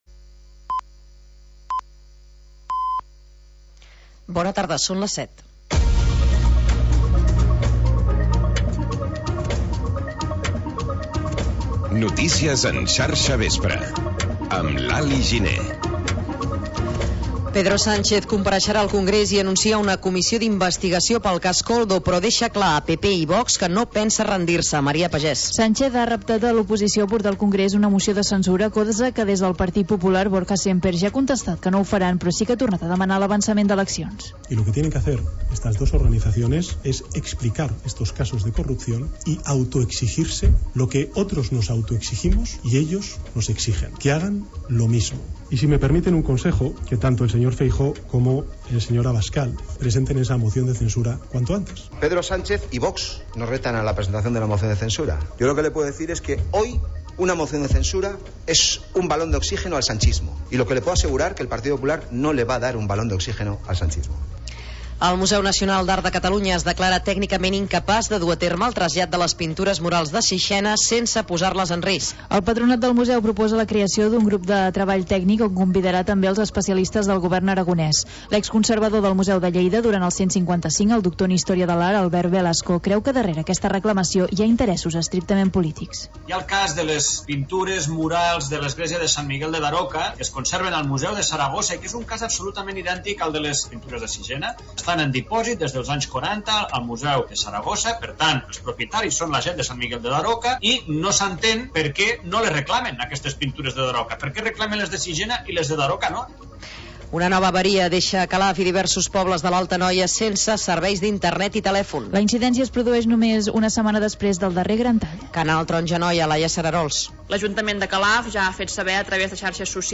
Informatiu territorial